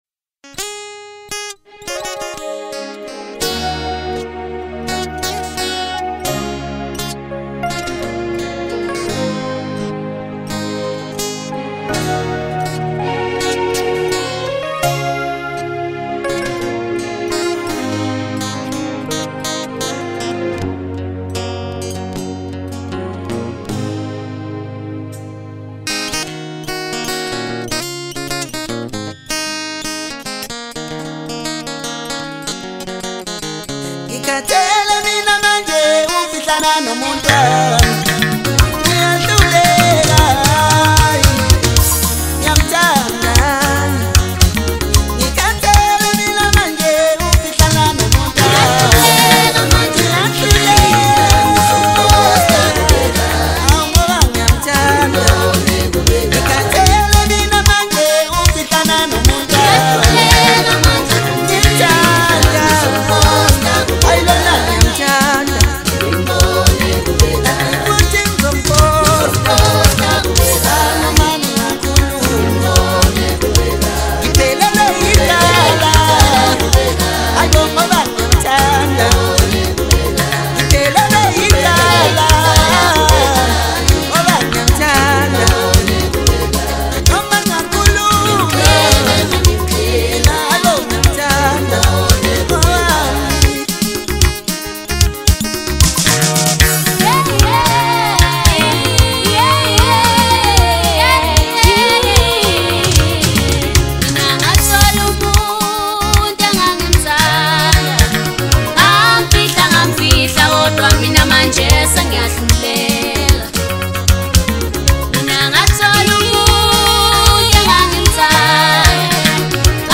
Home » Maskandi » DJ Mix
South African singer